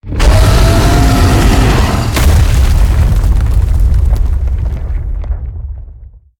File:Sfx creature iceworm death 01.ogg - Subnautica Wiki
Sfx_creature_iceworm_death_01.ogg